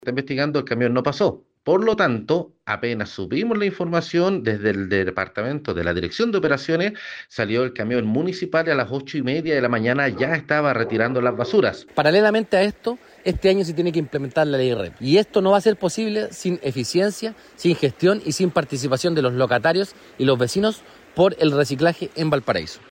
En la misma línea, los concejales Jorge López y Vicente Celedón señalaron que se trató de una situación excepcional ocurrida durante el domingo y confirmaron que se encuentran realizando diligencias para esclarecer lo sucedido y evitar que este tipo de episodios se repitan.
cu-mercado-cardonal-concejales.mp3